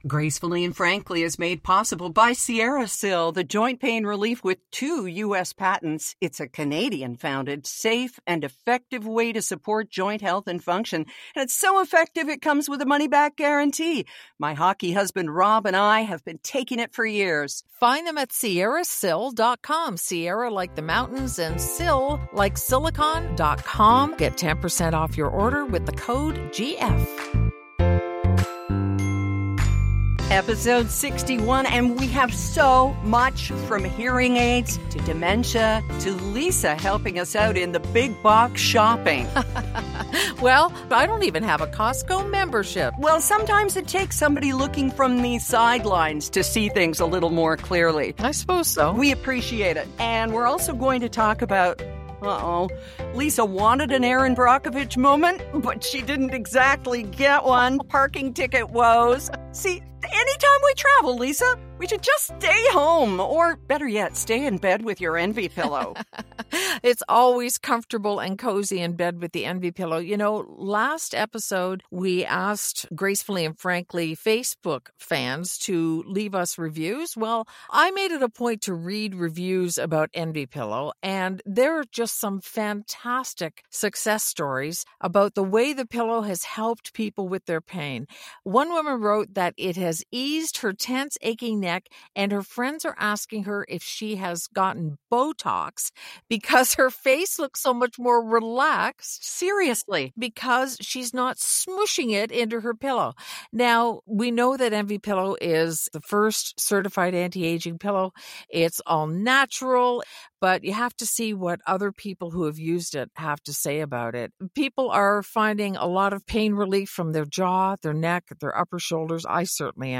In Ep 83 we laugh a LOT plus rephrasing to change your heart, a cool cooking hack (us?!?), actual “shrinkflation” proof, a DIFFERENT covid wave & (ugh) self-sabotage.